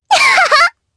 Sonia-Vox_Happy2_jp.wav